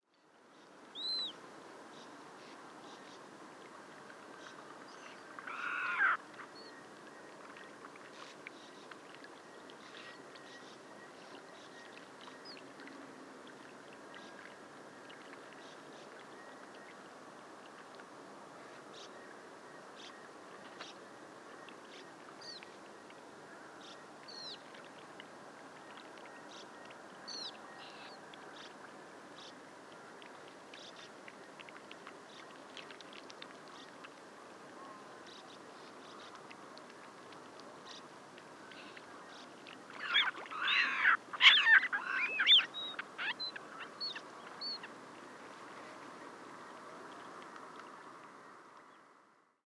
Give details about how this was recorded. PFR07943, 1-00, 130801, habitat recording Seelhausener See, Telinga parabolic reflector